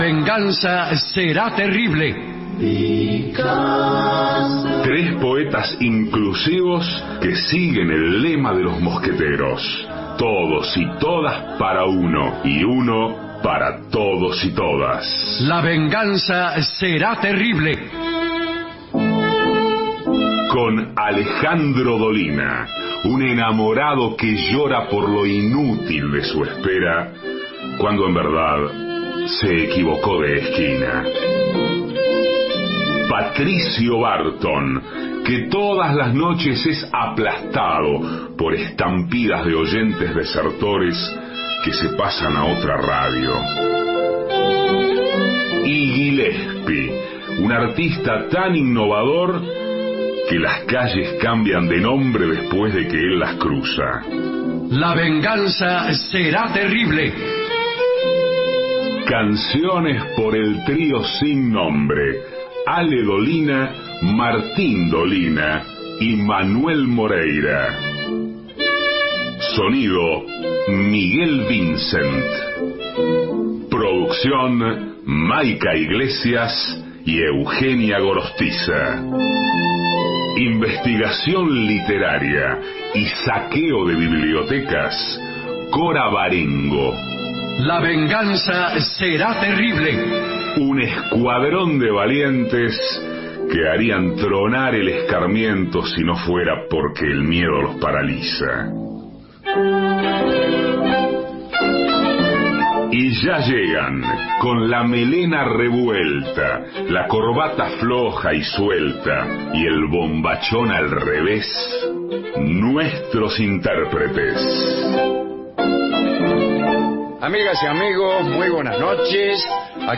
Estudios Radio AM 750